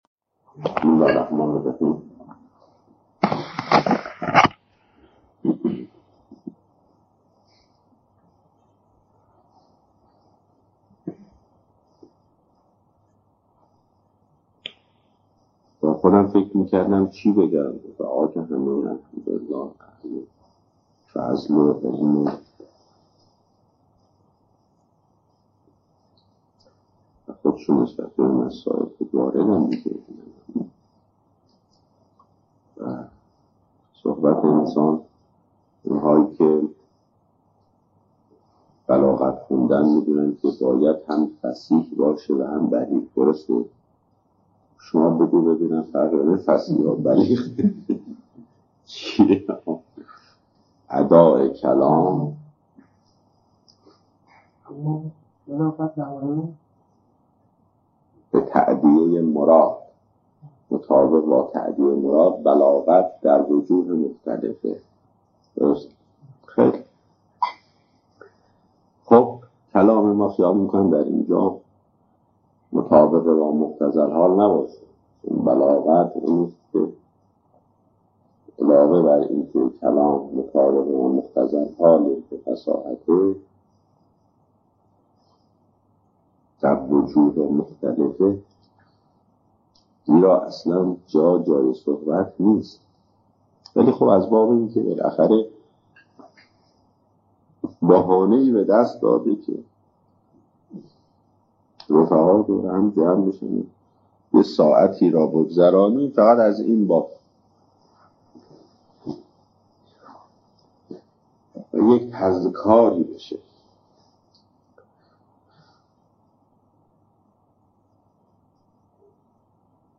سخنران